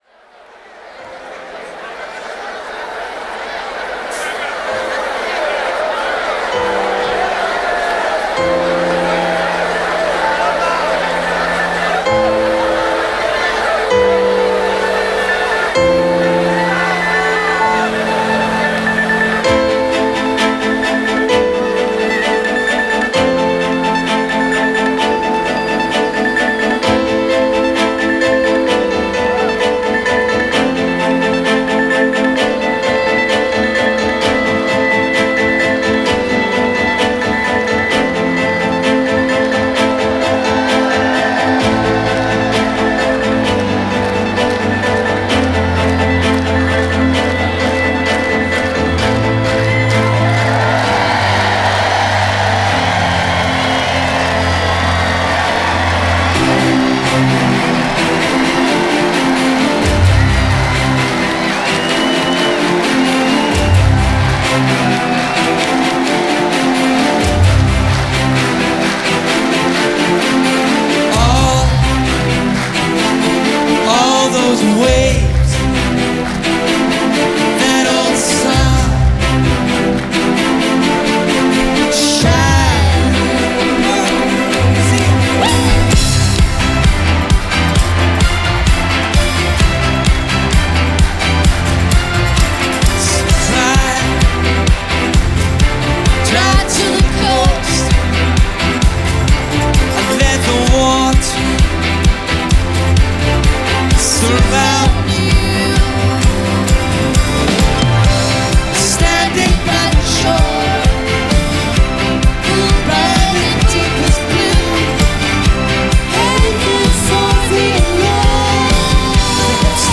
Scottish Sofisti-Pop with R&B leanings.